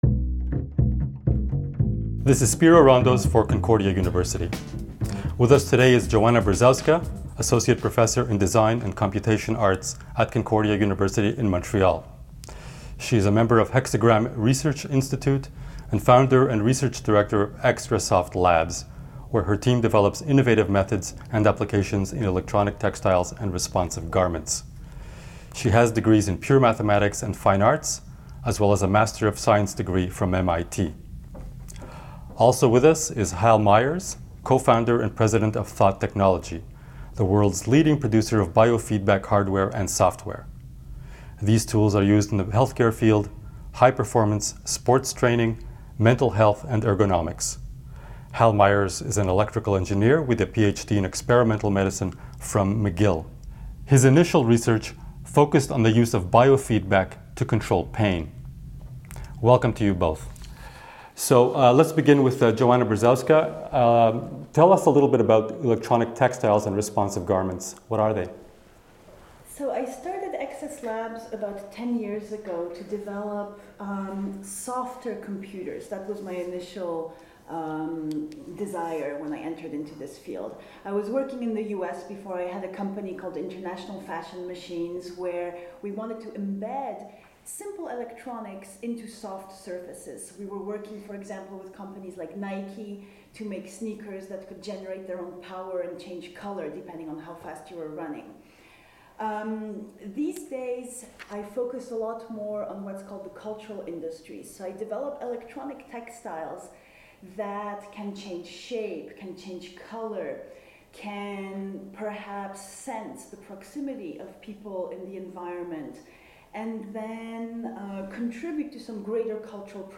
Beyond the Headlines is a relatively new publication that presents conversations with six Concordians who are not only making headlines, but are influencing the way we think. We have teamed them up with a community thought-leader to discuss a common topic, creating conversations intended to generate broader discussion and reflection.